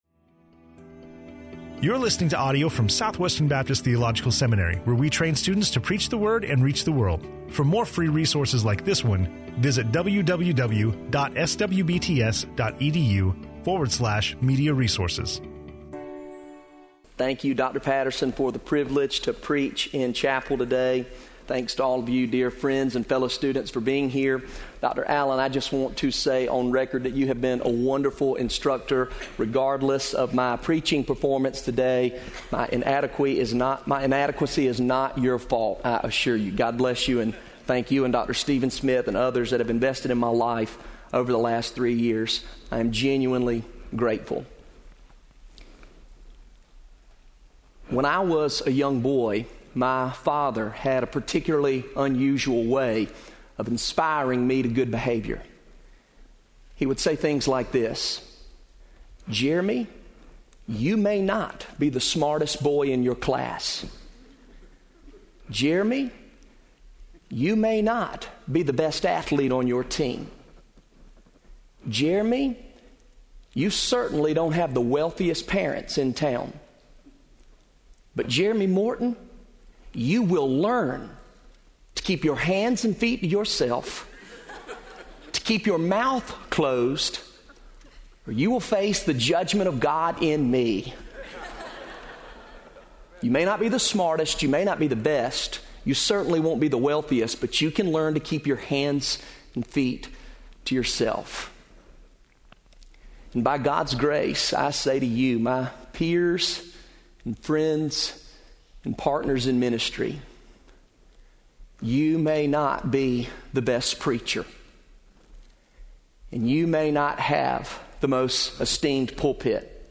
SWBTS Chapel Audio